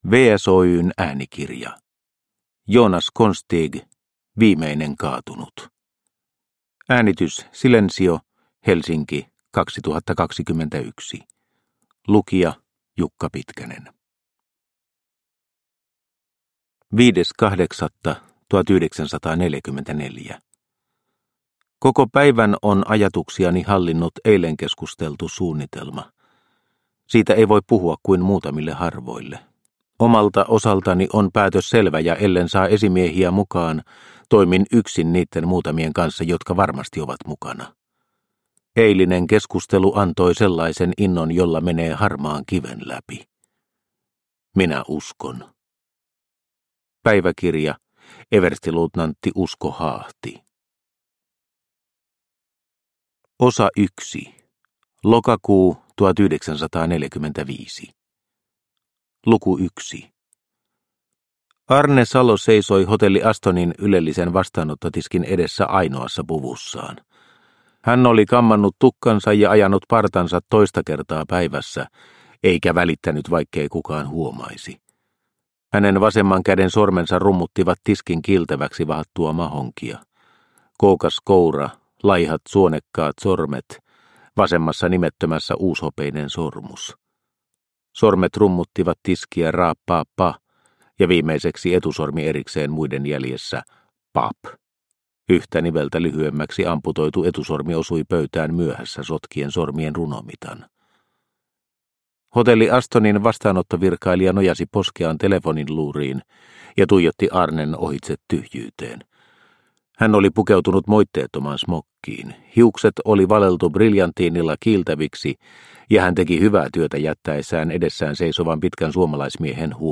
Viimeinen kaatunut – Ljudbok – Laddas ner